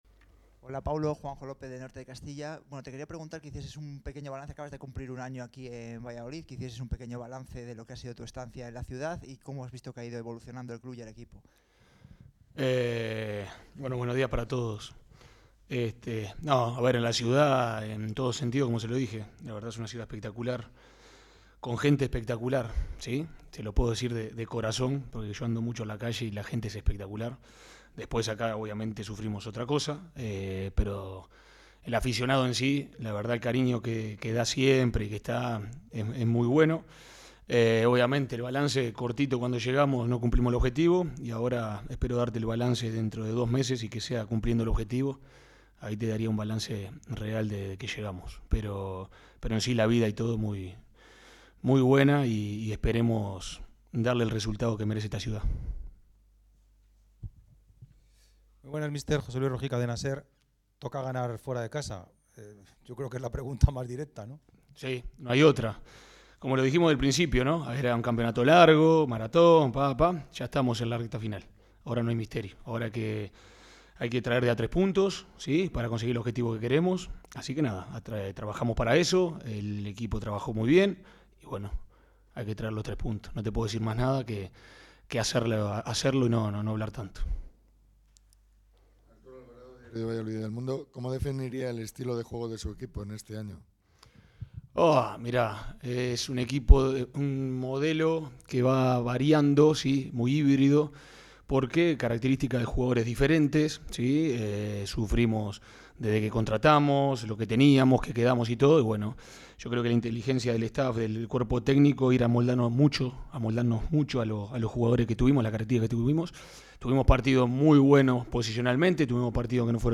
Rueda de prensa de Pezzolano antes de visitar al FC Cartagena | Real Valladolid CF | Web Oficial